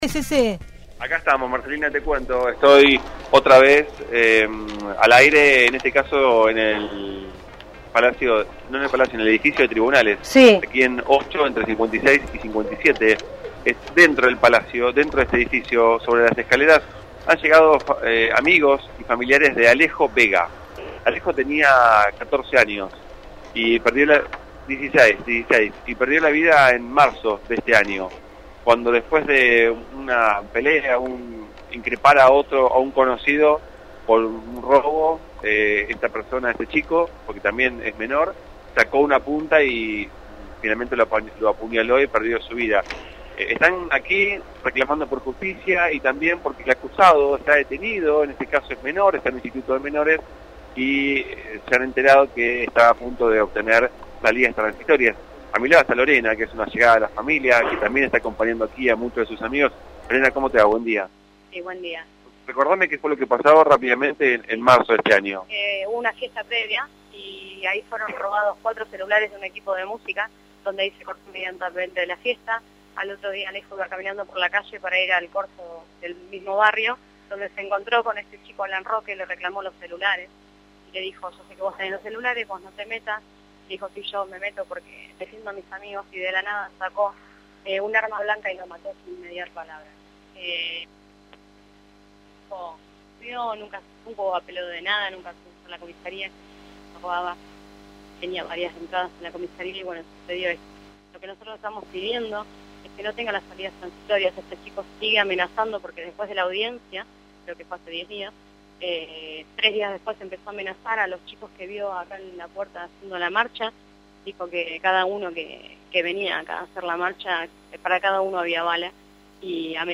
en el móvil de Radiópolis Ciudad Invadida (FM 107.5)